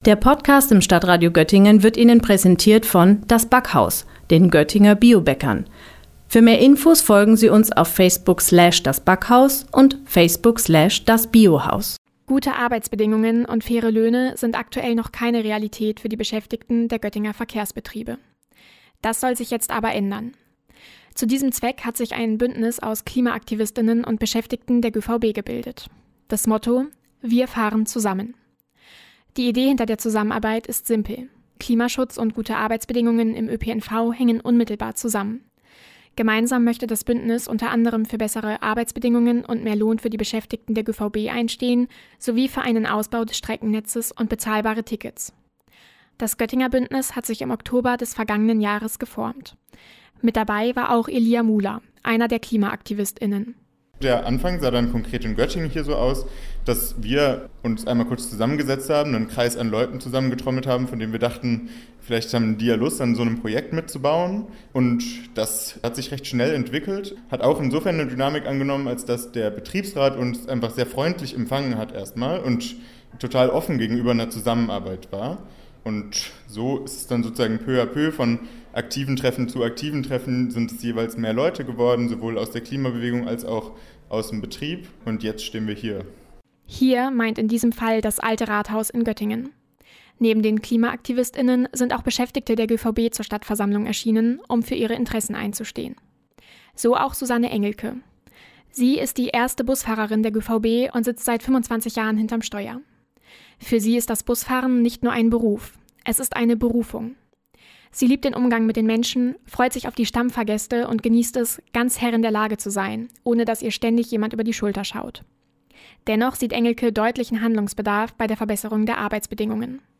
Aus diesem Grund hat sich das Bündnis „Wir fahren zusammen“ aus Klimaaktivist*innen und Beschäftigten der GöVB geformt, das gemeinsam die Missstände im ÖPNV zu beseitigen sucht. Bei einer Stadtversammlung Anfang Februar konnten sich Interessierte über die Anliegen der Bündnispartner informieren und Erfahrungsberichten aus dem Alltag von Busfahrer*innen lauschen.